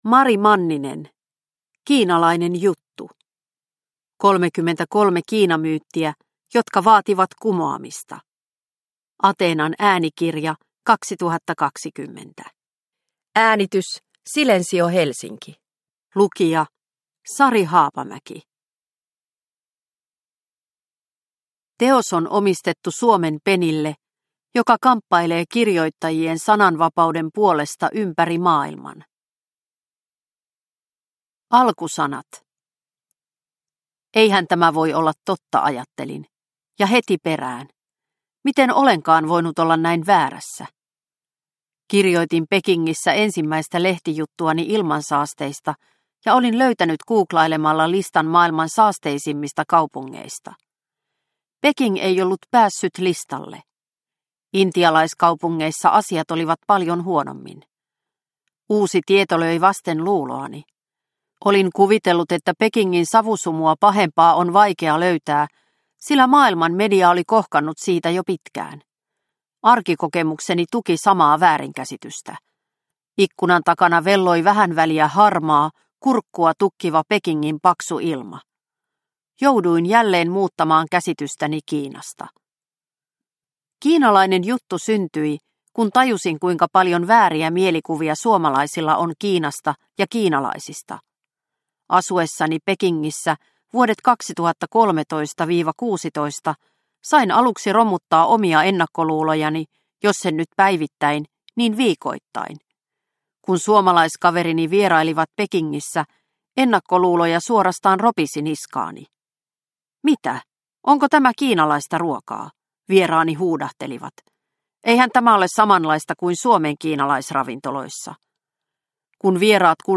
Kiinalainen juttu – Ljudbok – Laddas ner